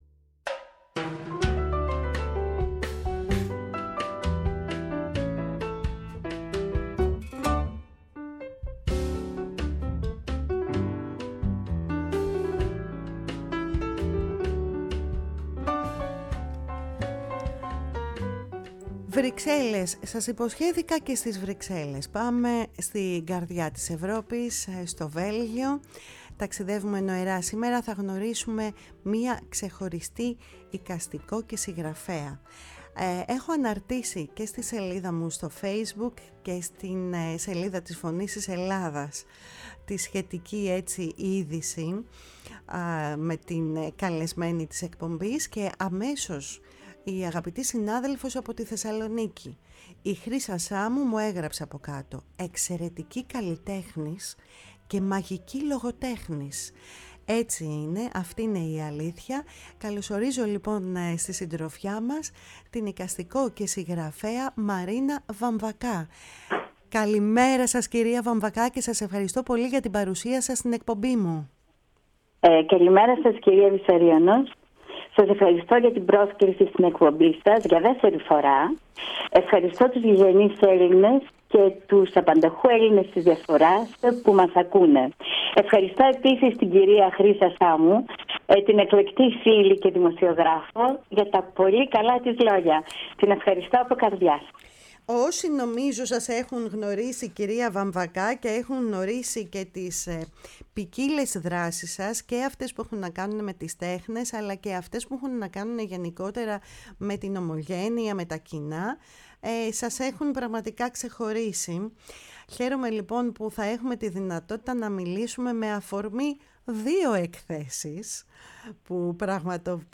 Η ΦΩΝΗ ΤΗΣ ΕΛΛΑΔΑΣ Κουβεντες Μακρινες ΣΥΝΕΝΤΕΥΞΕΙΣ Συνεντεύξεις